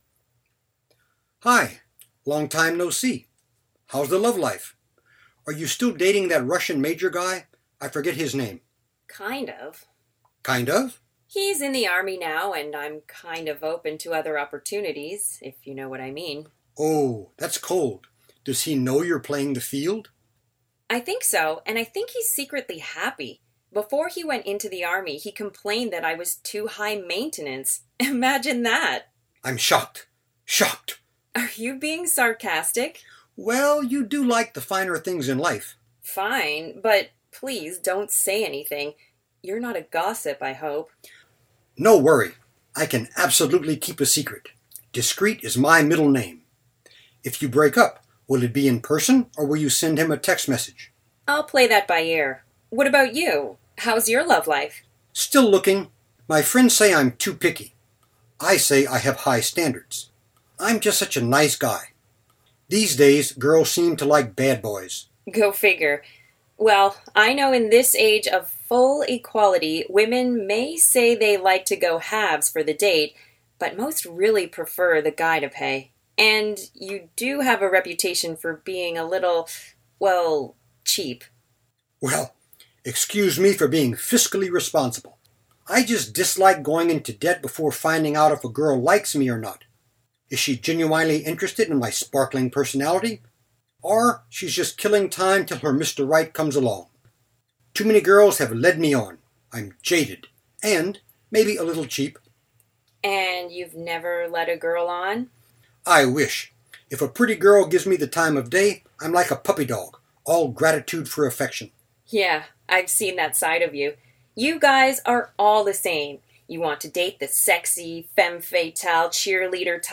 MS Word Essay Template Idioms Pronunciation Practice Listen and Repeat.
51 Conversation.mp3